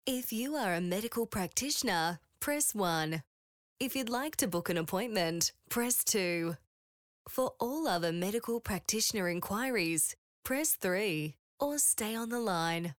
• On Hold
• Young
• Bright
• Fresh & Friendly
• Neumann TLM 103
• Own Home Studio